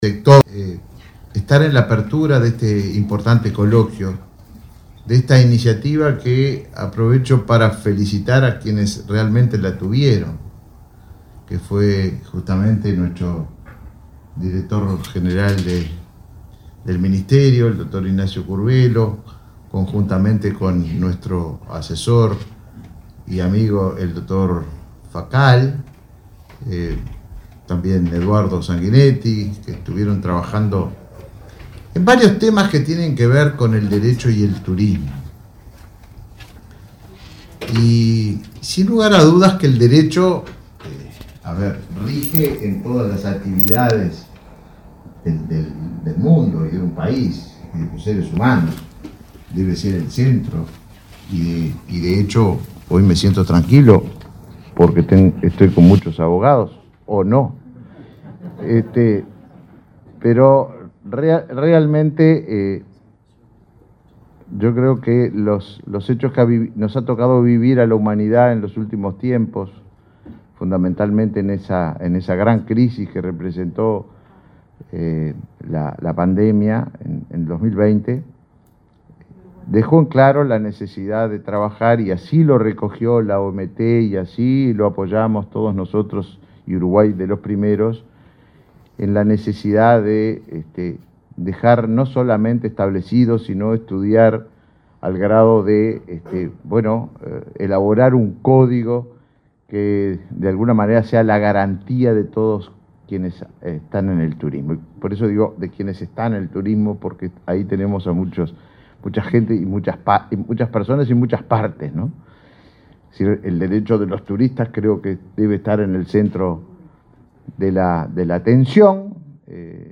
Palabras del ministro de Turismo, Tabaré Viera
Palabras del ministro de Turismo, Tabaré Viera 08/11/2023 Compartir Facebook X Copiar enlace WhatsApp LinkedIn Este miércoles 8 en Montevideo, el ministro de Turismo, Tabaré Viera, participó de la apertura del primer Coloquio Internacional de Derecho y Sostenibilidad en Turismo.